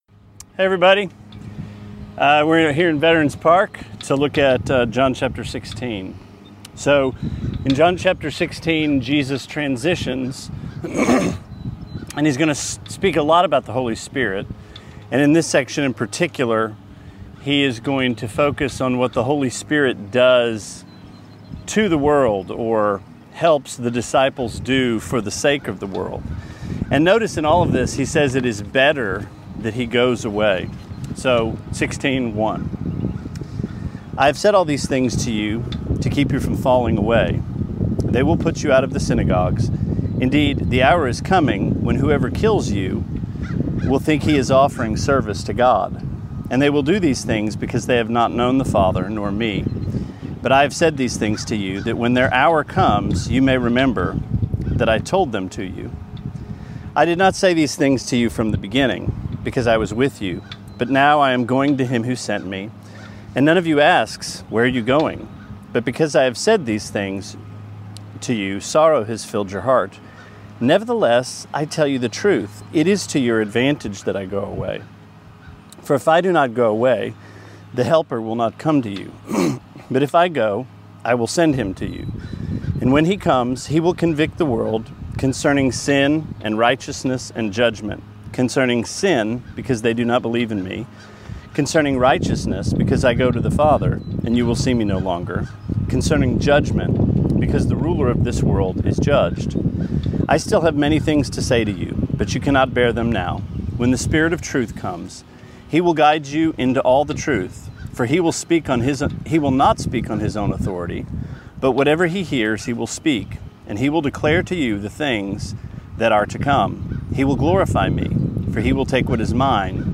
Sermonette 5/27: John 16:1-15: Convicting the World